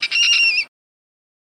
Mink Scream Higher Pitched Screech